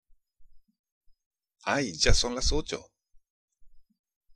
（アイ　ジャ　ソン　ラス　オチョ！）